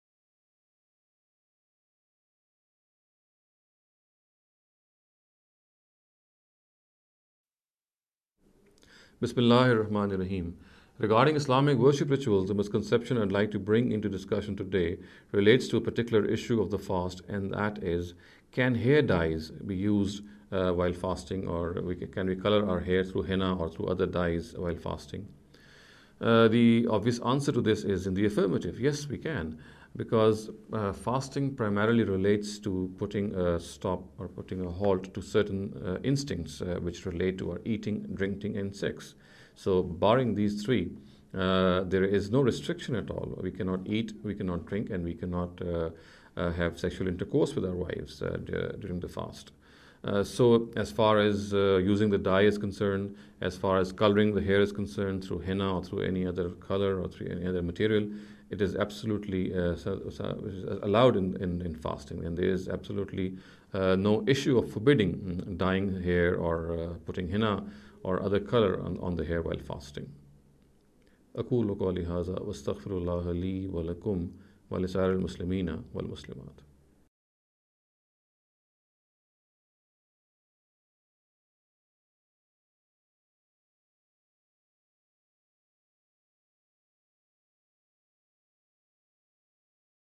This lecture series will deal with some misconception regarding the Islamic Worship Ritual.